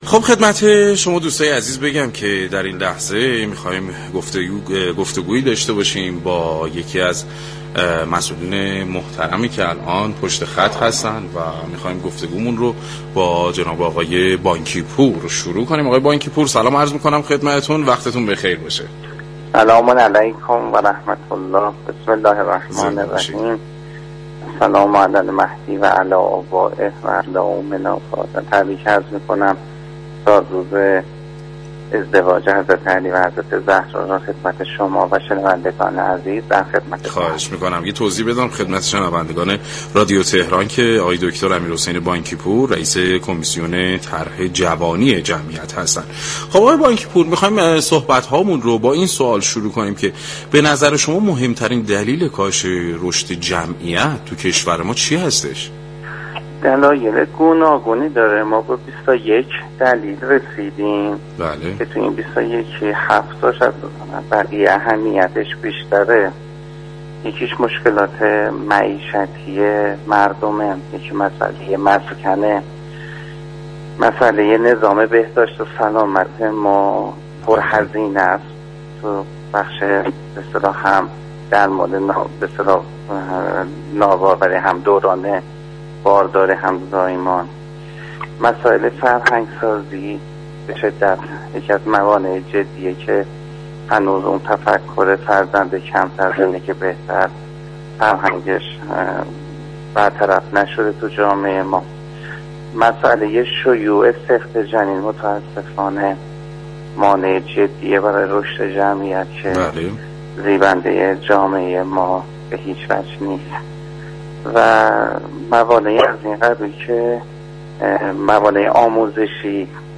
به گزارش پایگاه اطلاع رسانی رادیو تهران، امیرحسین بانكی‌پور رییس كمیسیون مشترك طرح جوانی جمعیت و حمایت از خانواده در گفتگو با سعادت آباد رادیو تهران درباره ی كاهش جمعیت گفت: بنا بر بررسی‌های انجام شده كاهش جمعیت 21 دلیل دارد كه 7 مورد آن از سایر موارد اهمیت بیشتری دارد.